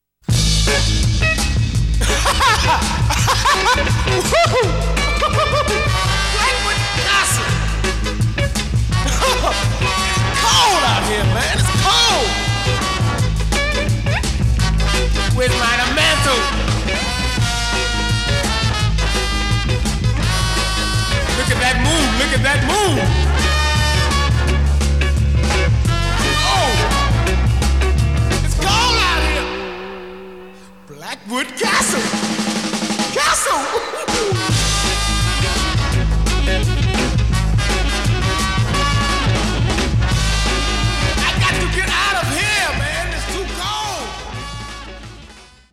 Originalmusik aus den deutschen Kult-Krimis